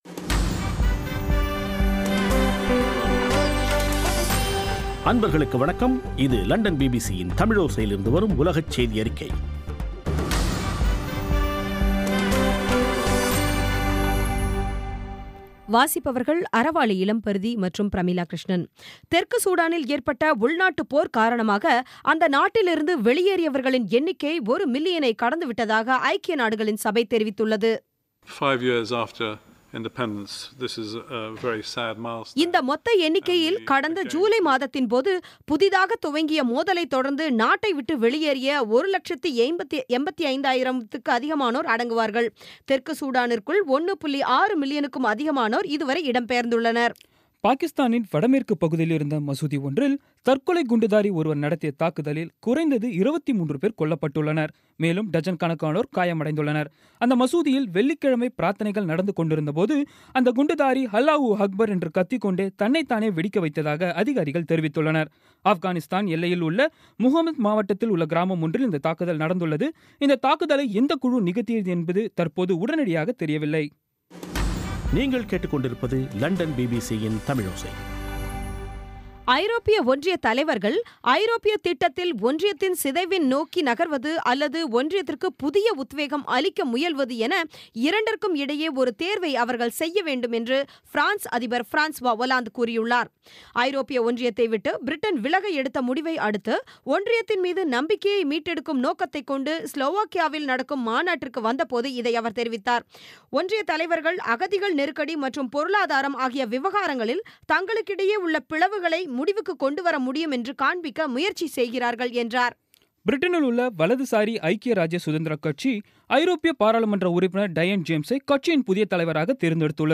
இன்றைய (ஆகஸ்ட் 16ம் தேதி ) பிபிசி தமிழோசை செய்தியறிக்கை